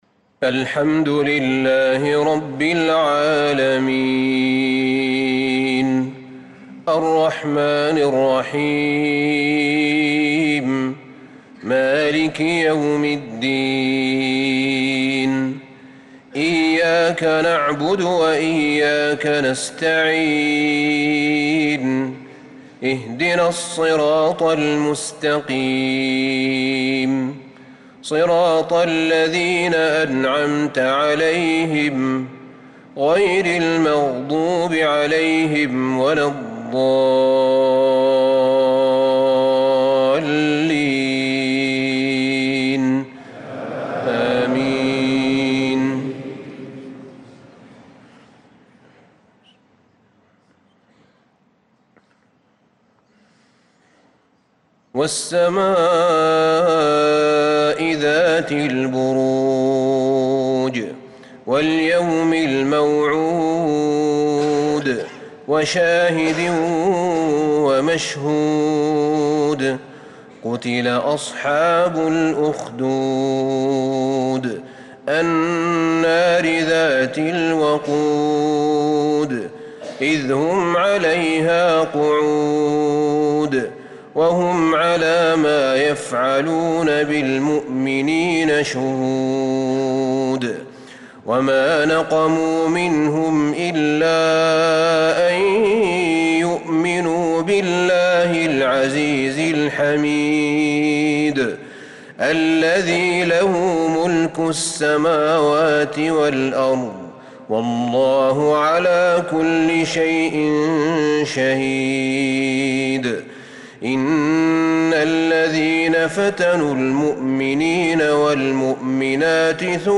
صلاة العشاء للقارئ أحمد بن طالب حميد 7 رجب 1445 هـ
تِلَاوَات الْحَرَمَيْن .